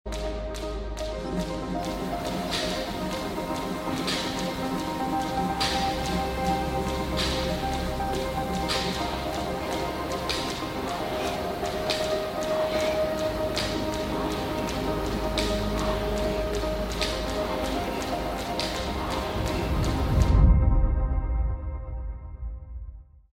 Sounds of the Gym. sound effects free download